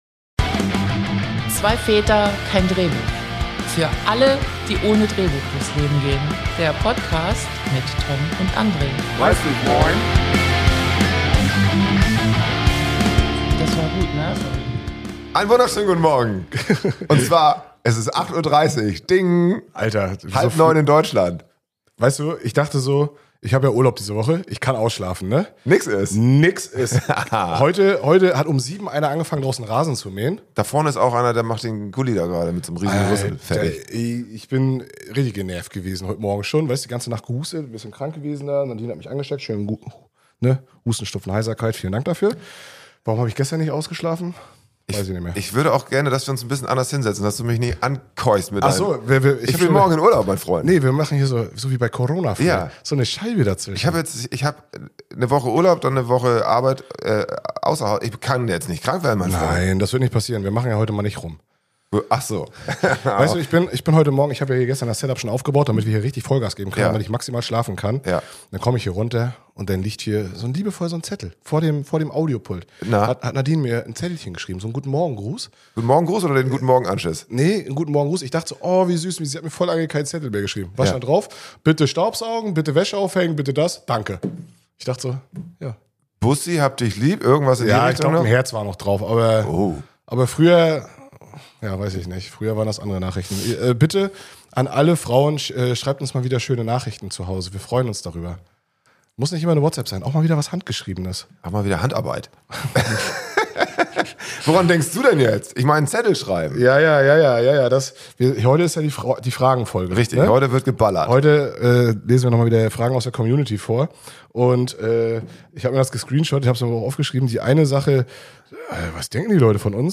Dazu gibt’s wie immer jede Menge Quatsch, Anekdoten und eine ordentliche Portion Selbstironie.